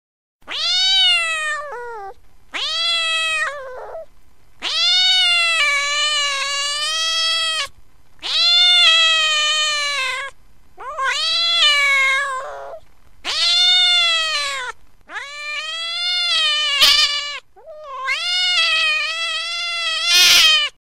دانلود صدای گربه وحشی و عصبانی در حال نشان دادن دندان ها از ساعد نیوز با لینک مستقیم و کیفیت بالا
جلوه های صوتی